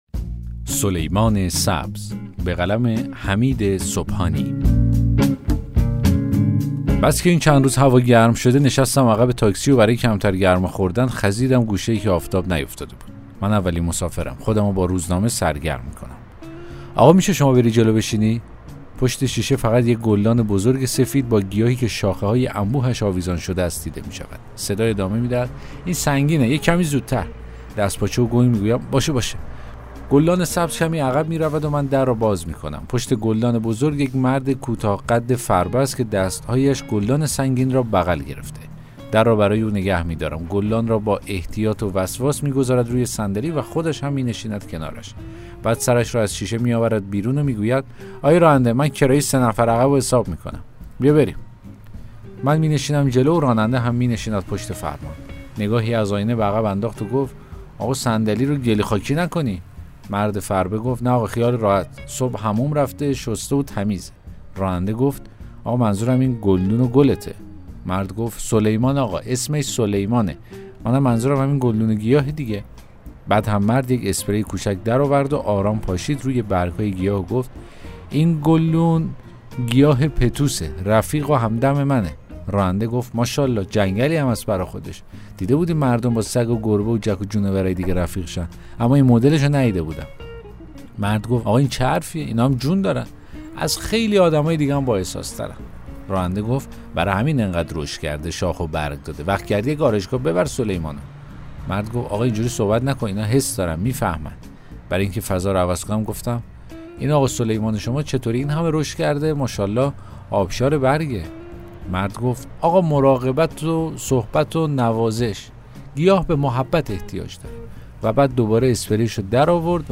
داستان صوتی: سلیمان سبز